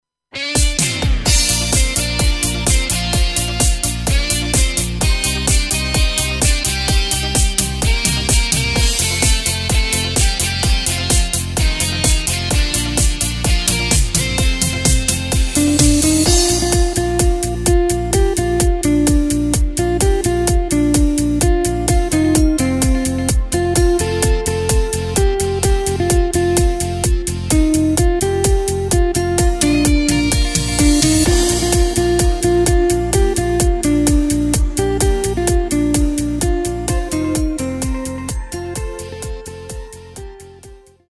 Takt:          4/4
Tempo:         128.00
Tonart:            Bb
Discofox aus dem Jahr 2024!